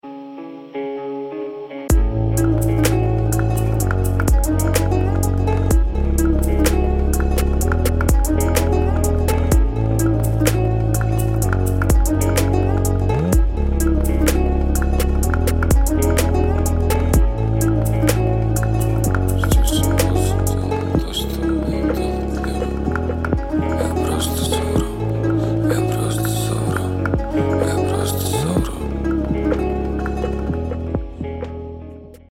• Качество: 128, Stereo
гитара
восточные мотивы
атмосферные
спокойные
Trap
струнные
Chill Trap